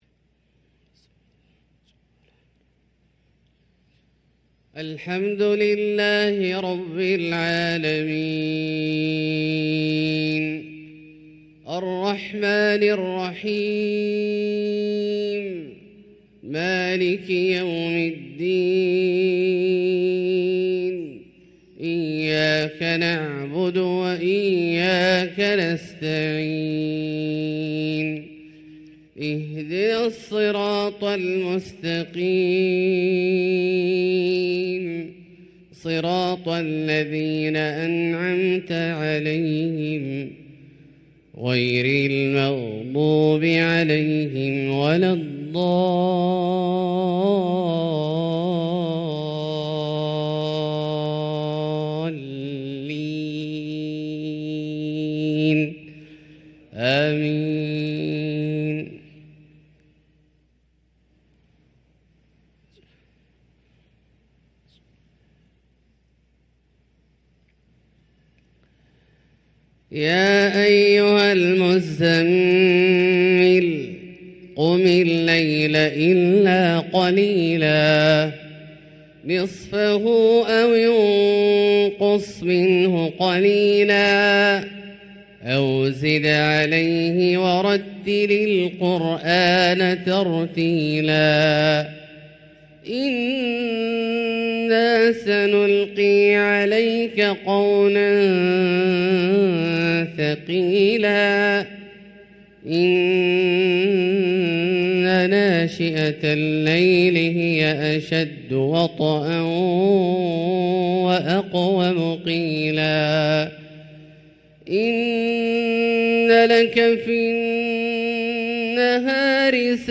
صلاة الفجر للقارئ عبدالله الجهني 1 صفر 1445 هـ